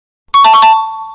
Extremely Loud Correct Buzzer